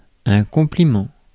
Ääntäminen
US : IPA : /ˈkɑmpləmənt/ RP : IPA : /ˈkɒmplɪmənt/